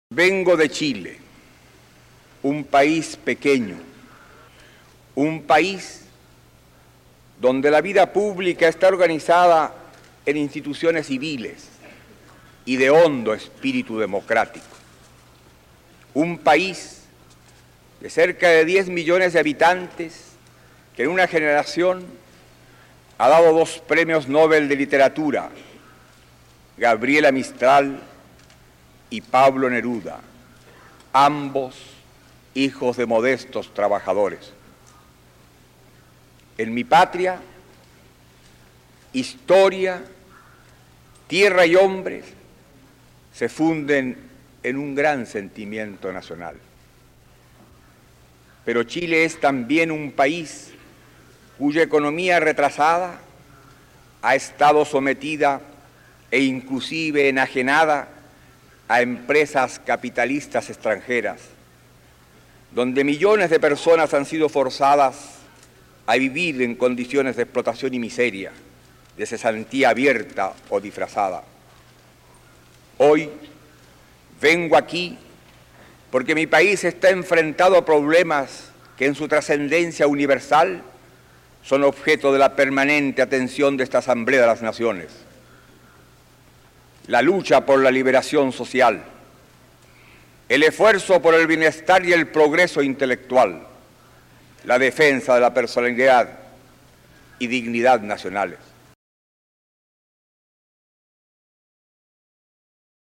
Salvador_Allende_UN.mp3